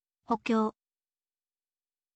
hokyou